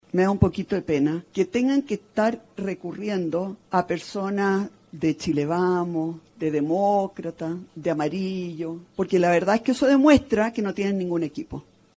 En ese contexto, y evidenciando algo de sarcasmo e ironía, comentó en “da un poquito de pena que tengan que estar recurriendo a personas de Chile Vamos, de Demócratas, de Amarillos, porque la verdad es que eso demuestra que no tienen ningún equipo”.